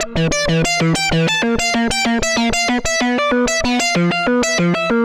Index of /musicradar/80s-heat-samples/95bpm
AM_CopMono_95-E.wav